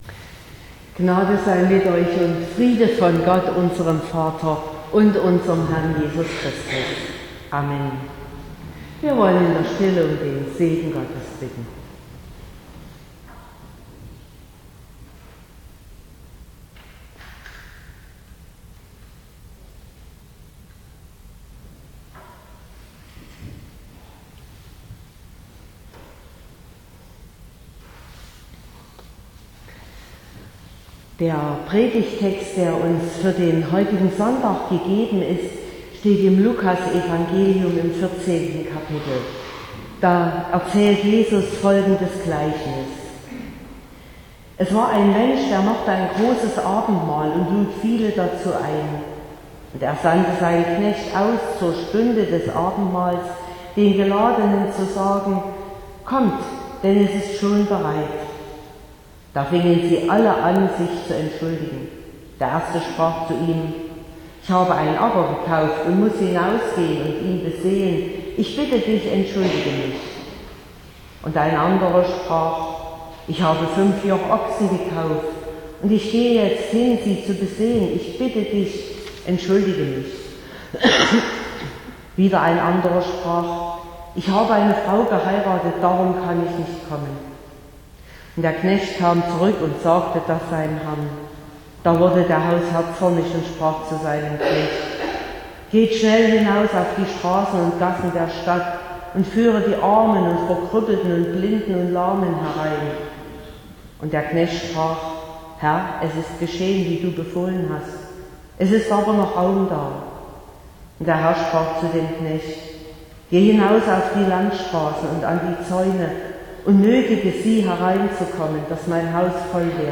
18.06.2023 – Gottesdienst mit Jubelkonfimation
Predigt und Aufzeichnungen